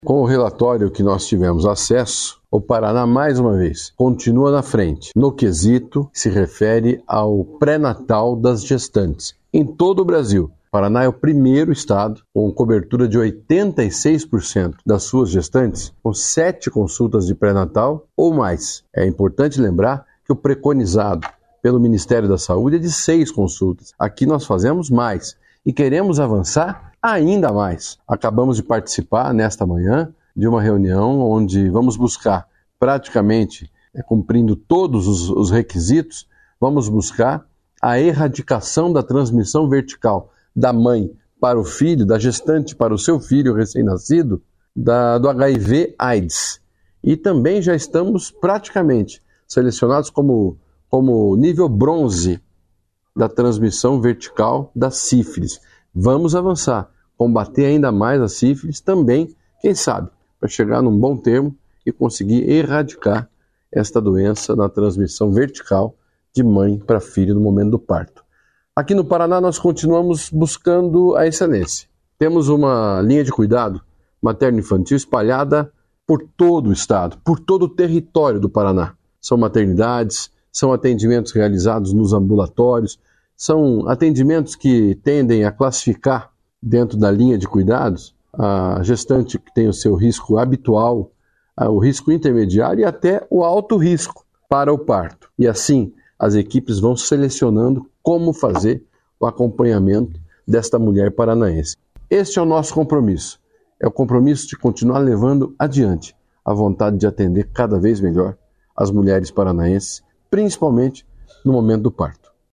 Sonora do secretário da Saúde, Beto Preto, sobre o Paraná ser o estado que mais realiza consultas de pré-natal pelo SUS